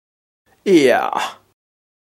Now you’re going to hear me saying some words and short sentences in my own language: Armenian.
Some of these intonations may sound new and unfamiliar to you.
I have a high-pitched voice, and I use my head voice more even while speaking, so I integrated the chest register too.